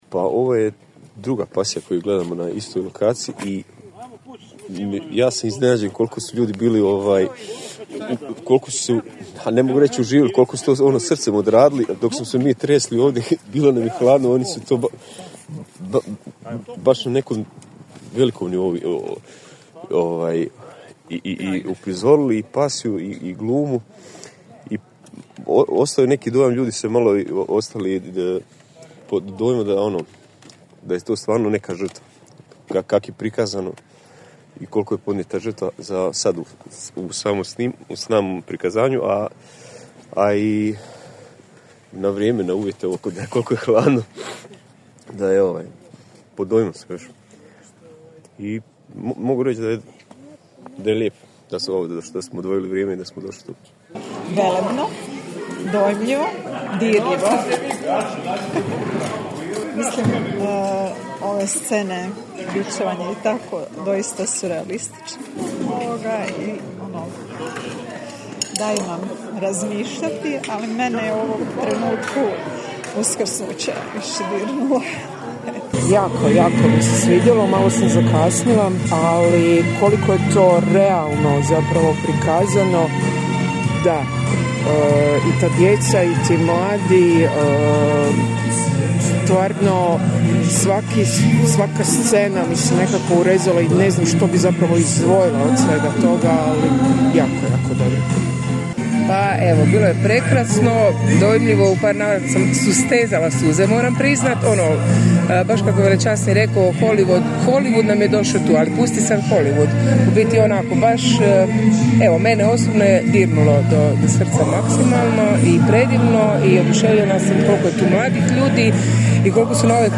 DOJMOVI PUBLIKE:
Karlovac_publika.mp3